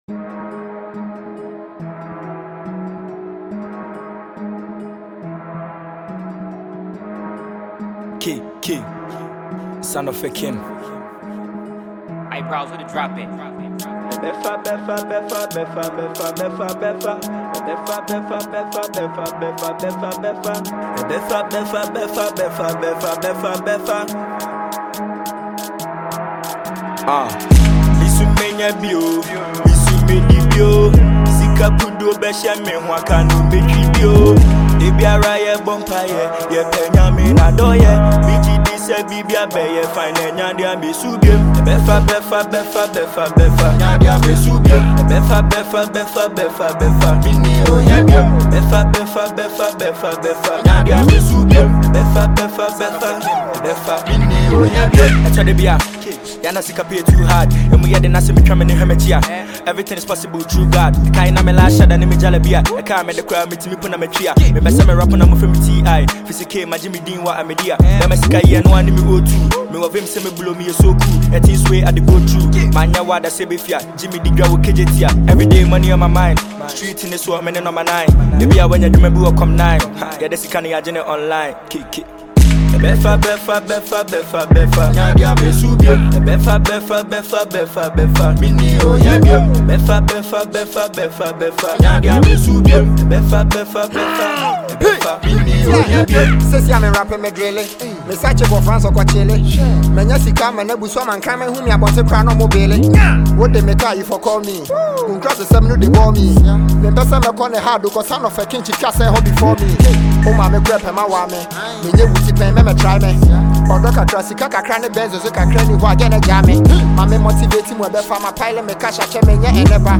Another fast-rising rapper in Ghana
street song